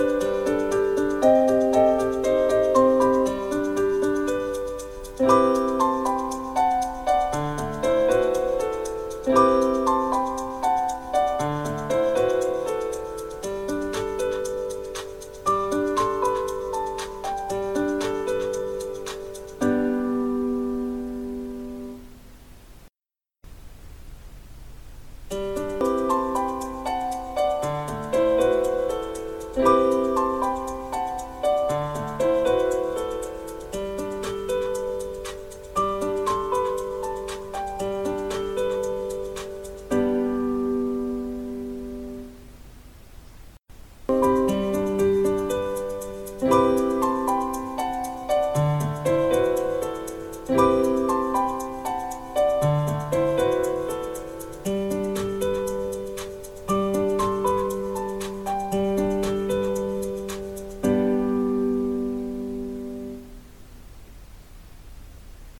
使用したマイクロホンの特性は単一指向性で前からの音だけを拾います。